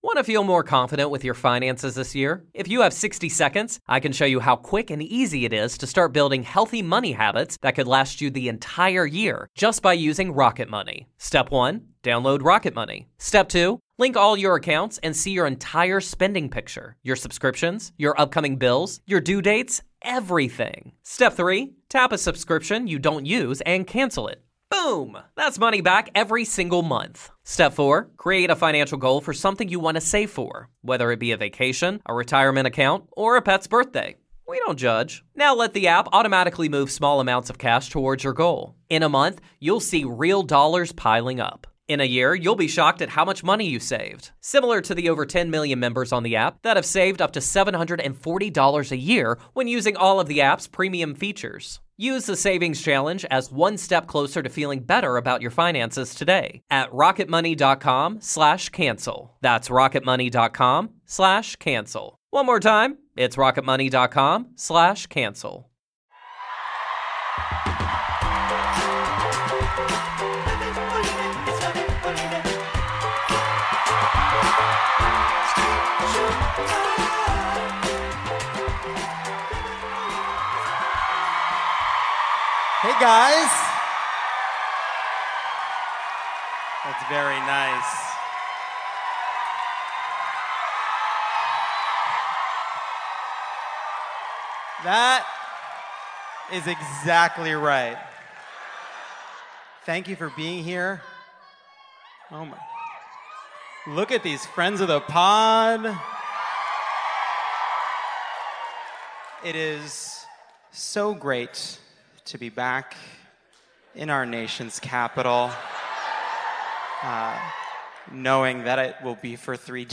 There Will Be Flood (Live from DC!)